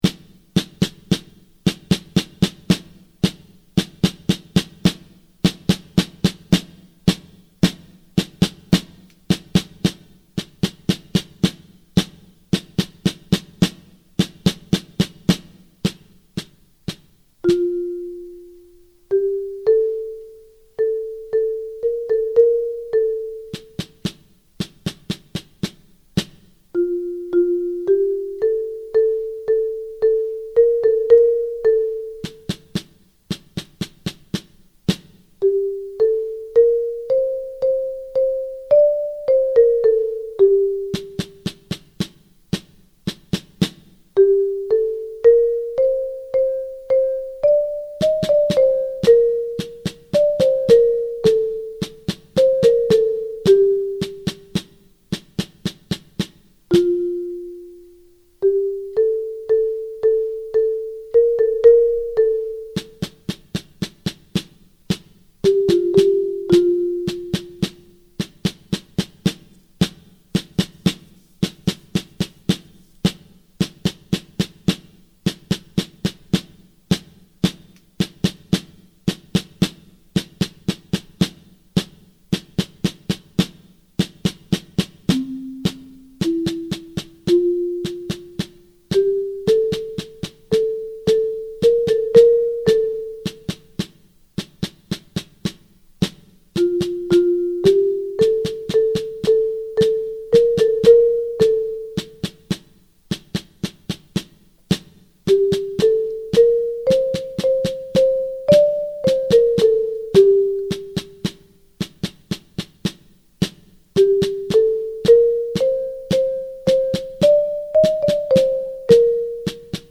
Mallet Percussion and Snare Drum Duet